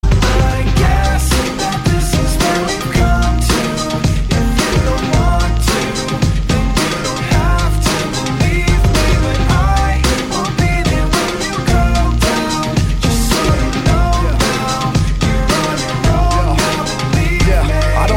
громкие
Alternative Rap